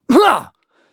Kibera-Vox_Attack1.wav